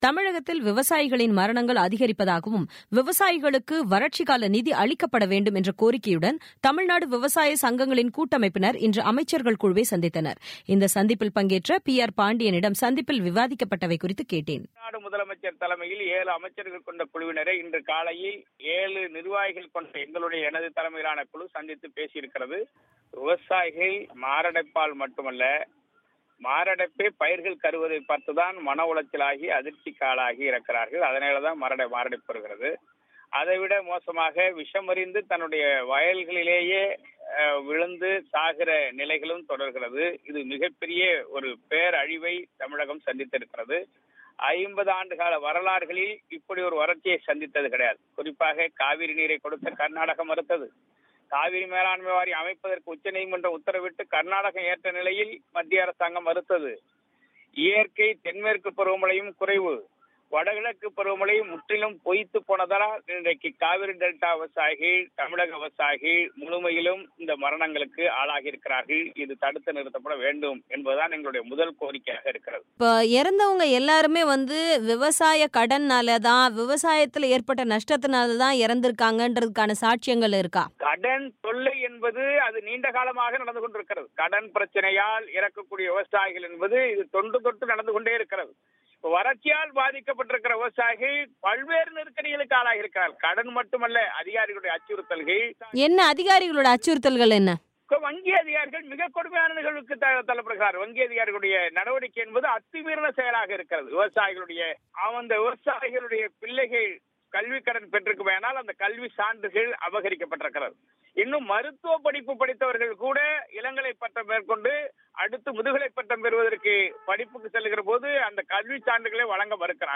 பேட்டி